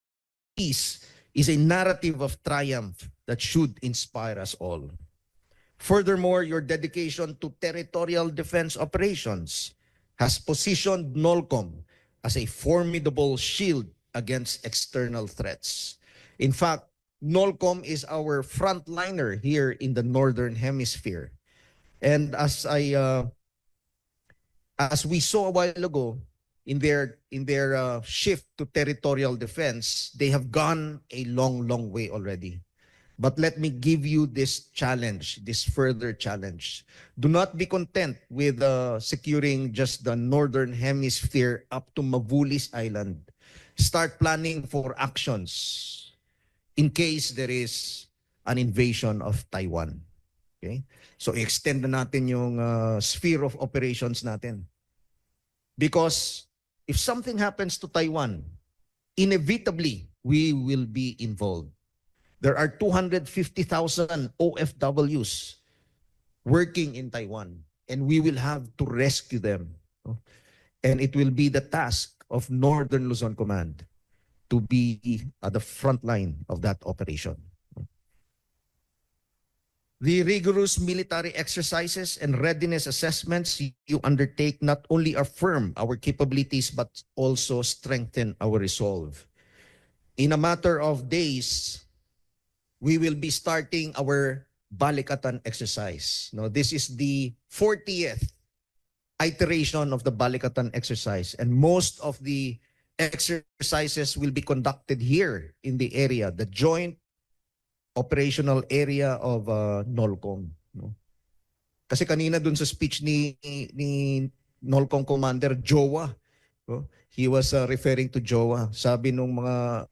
General Romeo S. Brawner
"Peace Through Strength": Excerpted Remarks from Address at NOLCOM
delivered 1 April 2025, Camp Servillano Aquino, Tarlac City, Republic of the Philippines